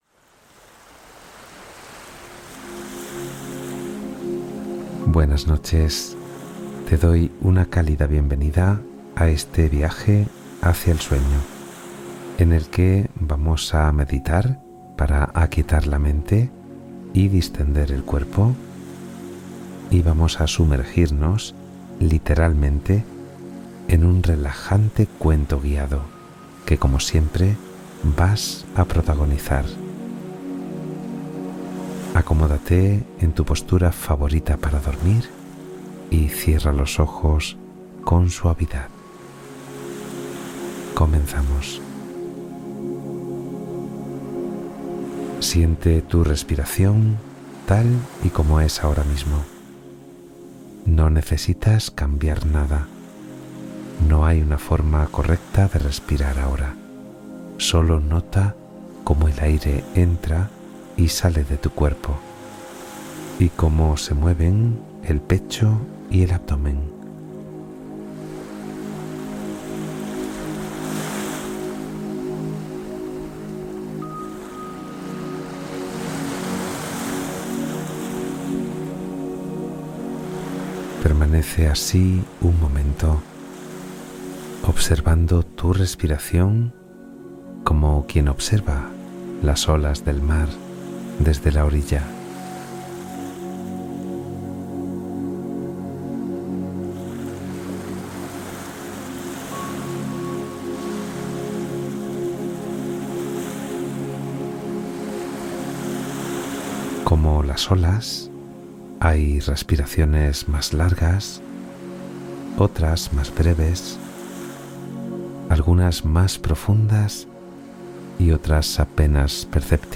Viaje Guiado hacia el Sueño Profundo